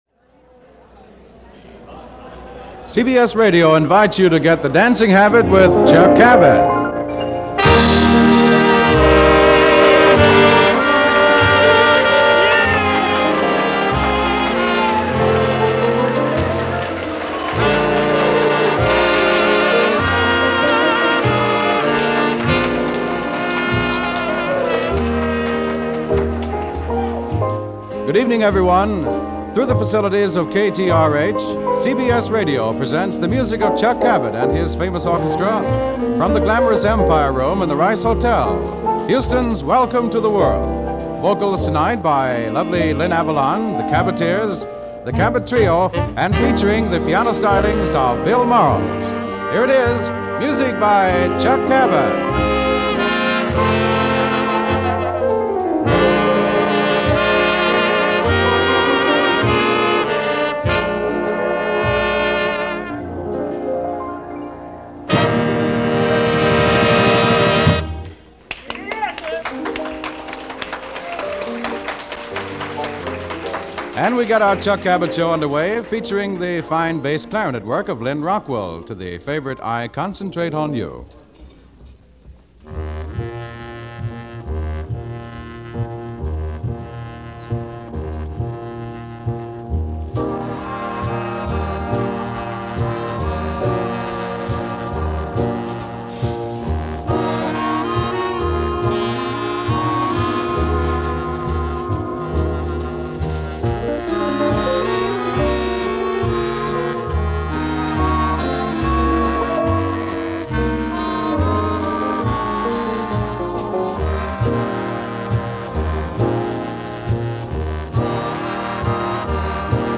live from The Rice Hotel, Houston Texas